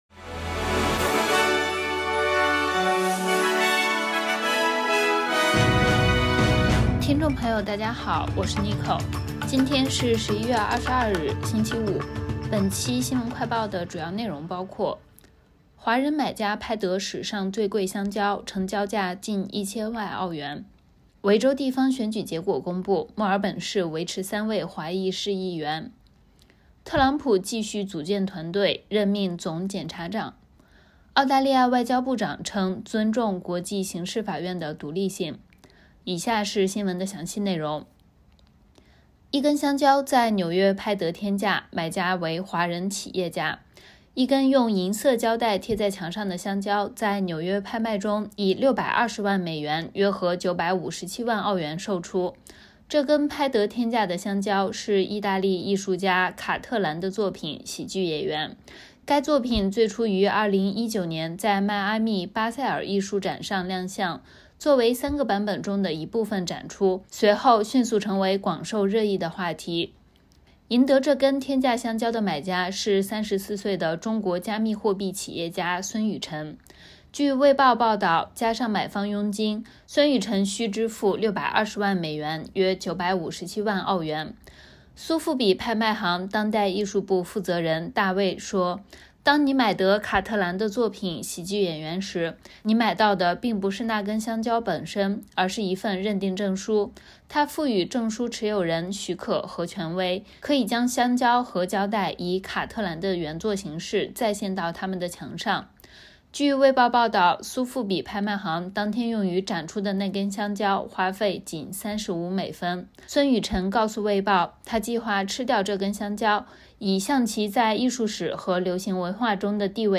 SBS 新闻快报